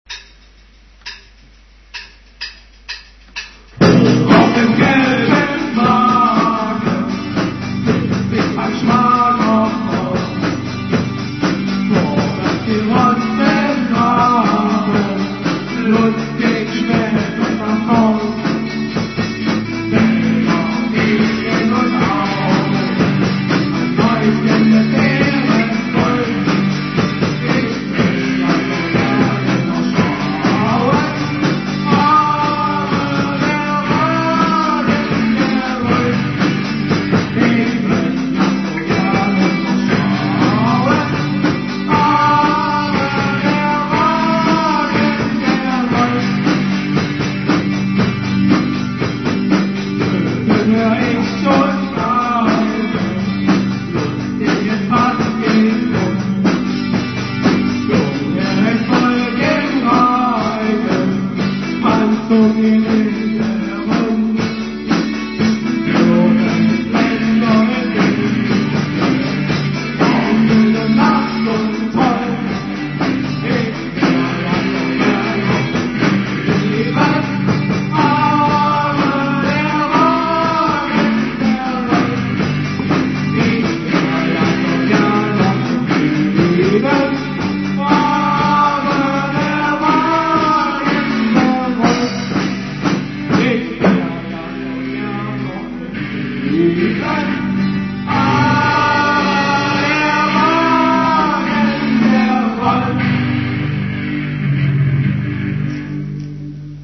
Gnadenlos schlecht aber Kult
Gesang
Gitarre
Schlagzeug
mono